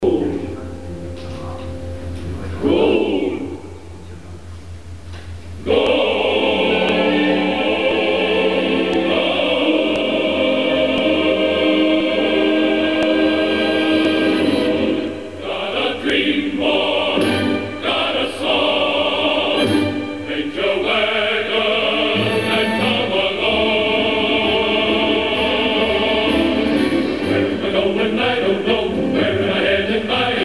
VO _____________ Thème musical